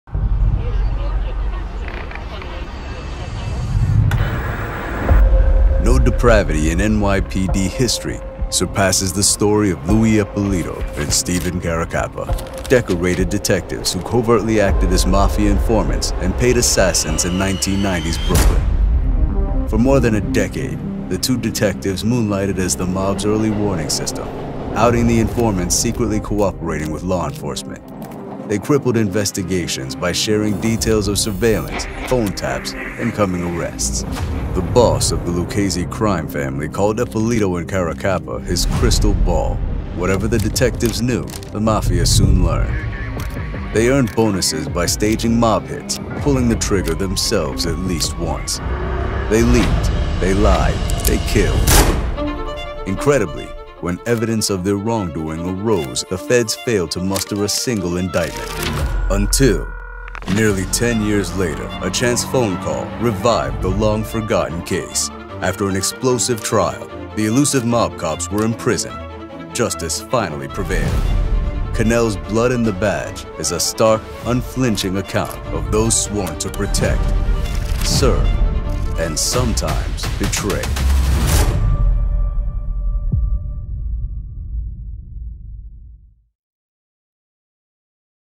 Professional American Male Voice Actor | Commercial, E-Learning & Corporate Narration
Blood and the Badge Trailer
Known for a deep, authoritative voice as well as warm, conversational, and relatable reads, I provide versatile performances tailored to luxury brands, tech explainers, financial narration, medical content, network promos, political campaigns, and cinematic trailers.